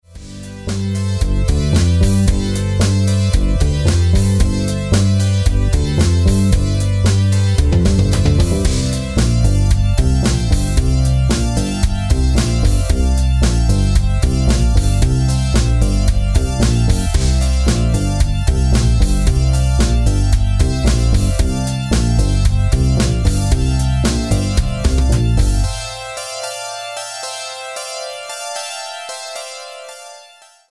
BPM: 113